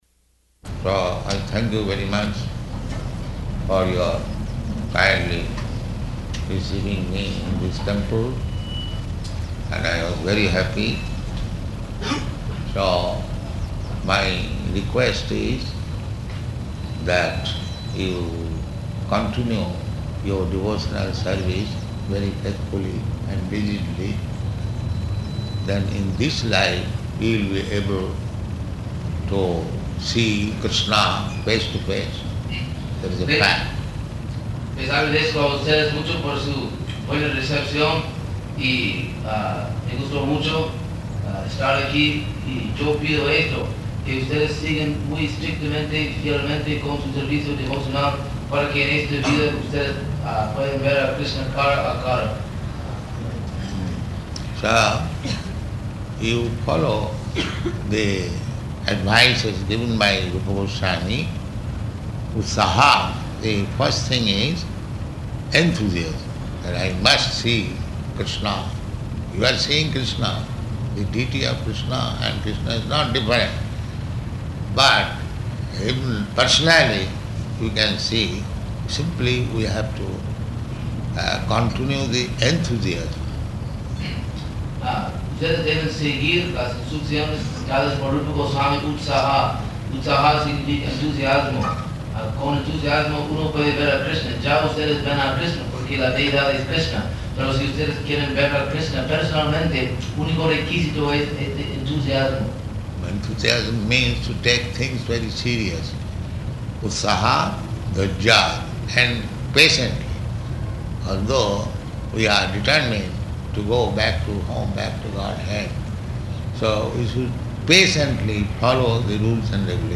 Departure Lecture
Location: Caracas